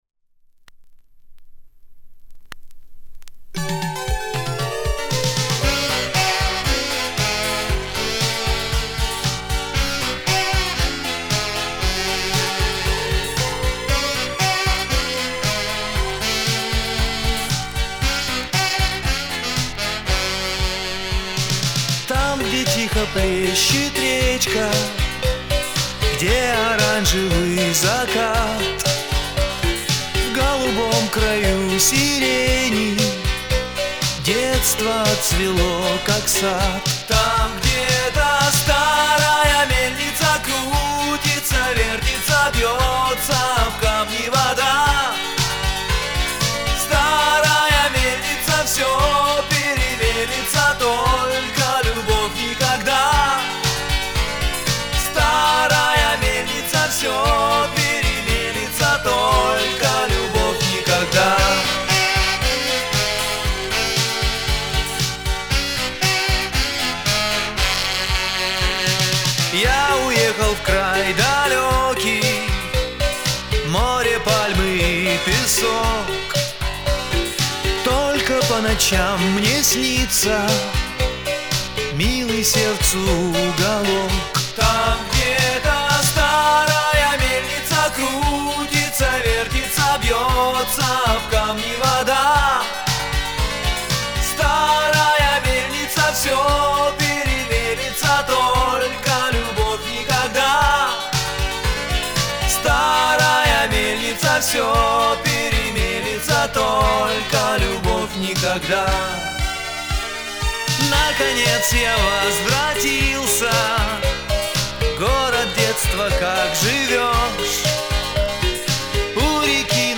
Оцифровка винила 1987 года.